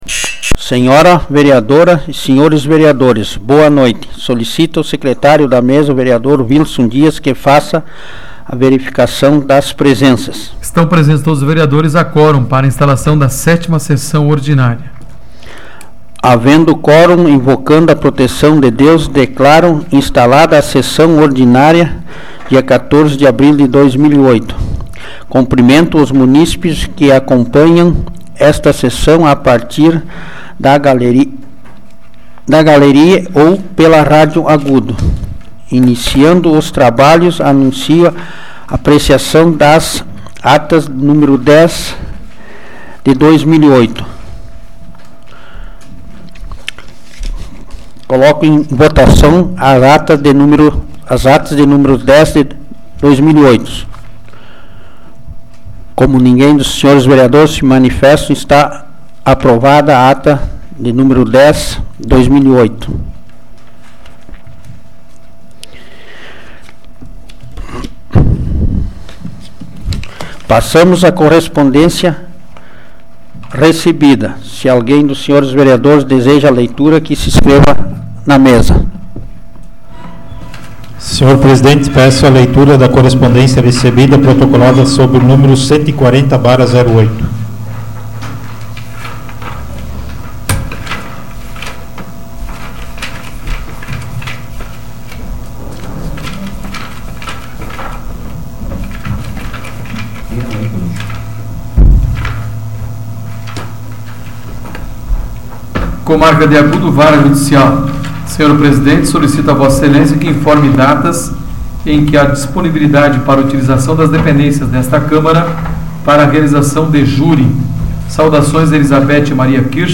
Áudio da 118ª Sessão Plenária Ordinária da 12ª Legislatura, de 14 de abril de 2008